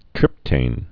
(trĭptān)